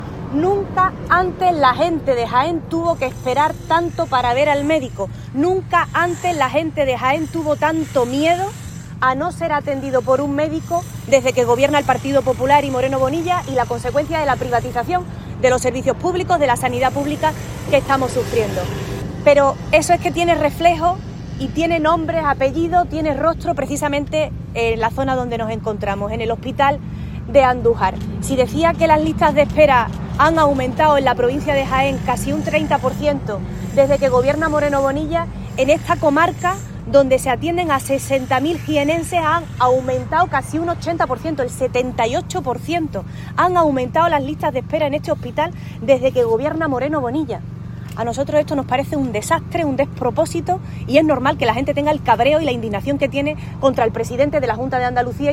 La responsable socialista hizo estas declaraciones a las puertas del Hospital Alto Guadalquivir de Andújar, que podría ser rebautizado como “Hospital de Alta Colocación del PP” por la cantidad de personas vinculadas a este partido que han encontrado puesto en este centro.